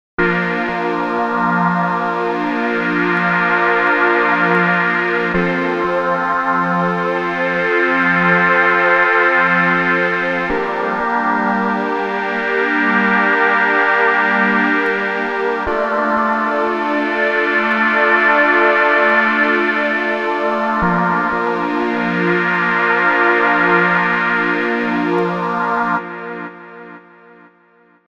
pad + phaser